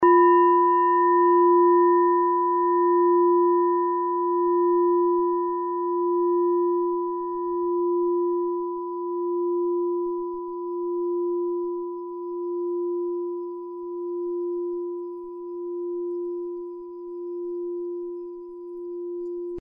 Klangschale Nepal Nr.38
Klangschale-Gewicht: 1390g
Klangschale-Durchmesser: 16,4cm
(Ermittelt mit dem Filzklöppel)
klangschale-nepal-38.mp3